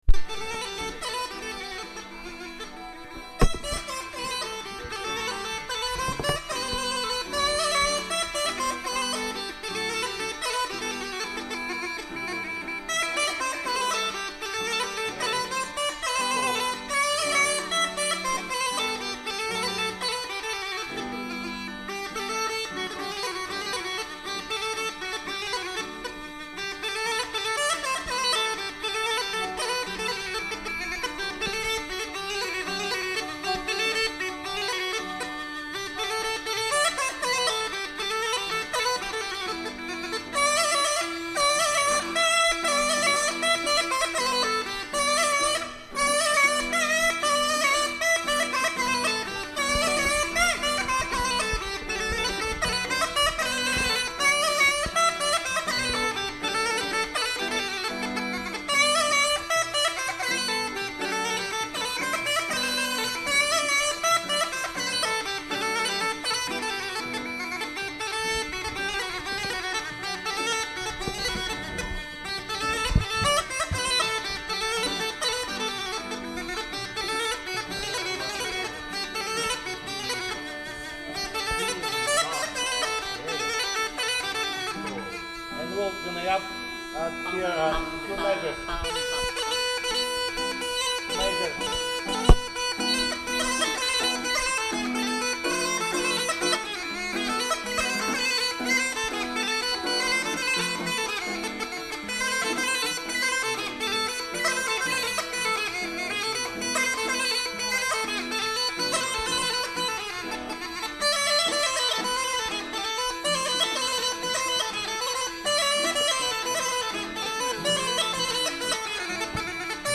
unknown tambura player